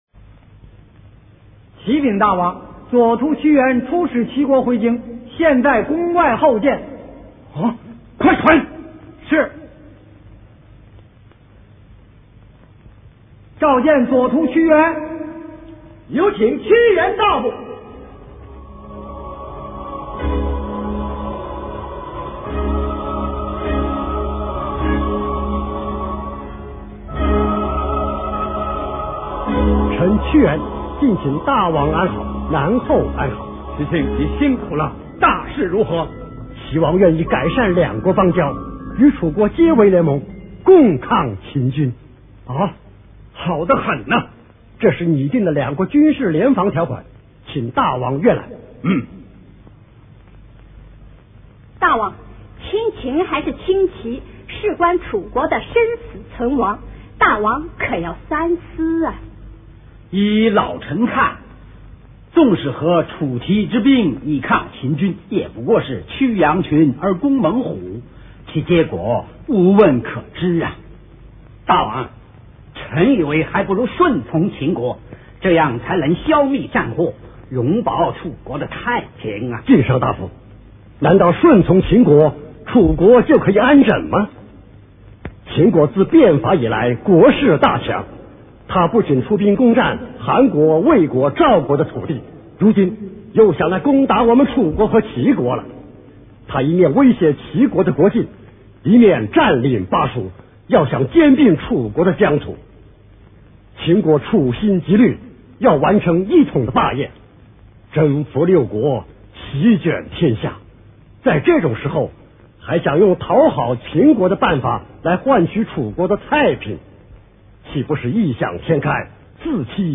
老电影《屈原》录音